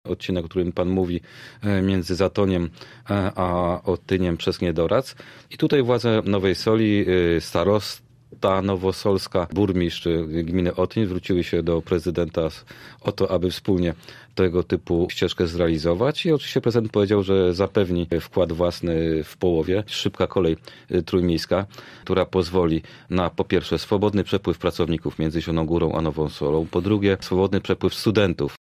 Zielona Góra, Nowa Sól i Sulechów chcą rozwijać infrastrukturę śródmiejską. W planach jest połączenie ścieżek rowerowych między naszym miastem a Nową Solą oraz szybka kolej obsługująca dwie wspomniane miejscowości i Sulechów. Mówił o tym w „Rozmowie Pkt 9” Krzysztof Kaliszuk, wiceprezydent Zielonej Góry: